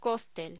Locución: Cóctel
voz